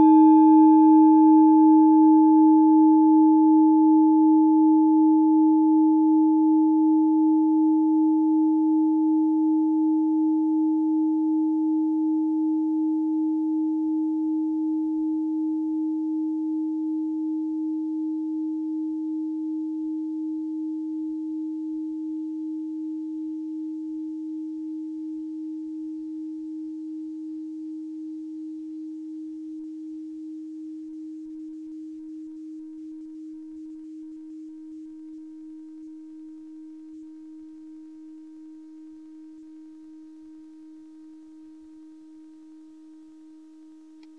Diese Klangschale ist eine Handarbeit aus Bengalen. Sie ist neu und wurde gezielt nach altem 7-Metalle-Rezept in Handarbeit gezogen und gehämmert.
Hörprobe der Klangschale
Was den Klang anbelangt, sind handgearbeitete Klangschalen immer Einzelstücke.
Filzklöppel oder Gummikernschlegel
Hören kann man diese Frequenz, indem man sie 32mal oktaviert, nämlich bei 154,66 Hz. In unserer Tonleiter befindet sich diese Frequenz nahe beim "D".